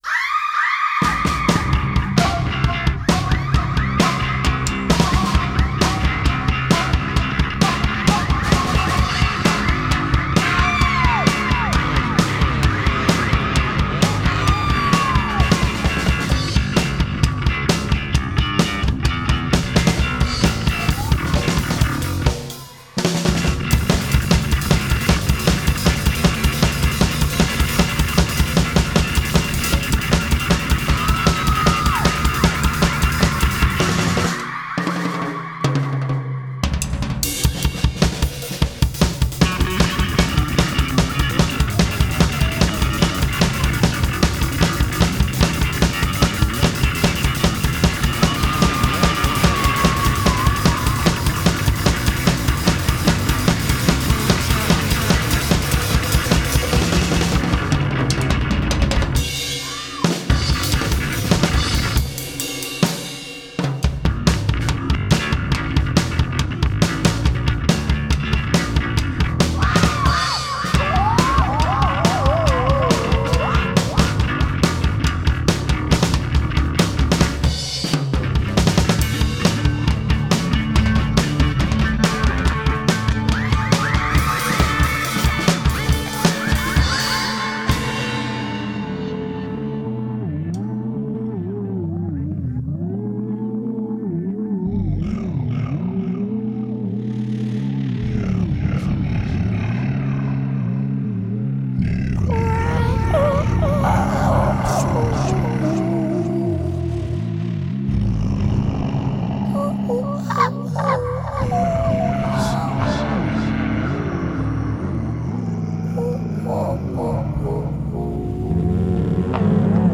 keine struktur, riffs oder alles was nach arbeit und disziplin riecht. einpegeln, in ruhe bier trinken, auf rec drücken und jede(r) spielt/singt wassa will.
nur bleed wird besetigt und fertig sind die "songs". auch alle effekte sind "geprintet", und die konzerte laufen auch so.
da sind wir als trio einig.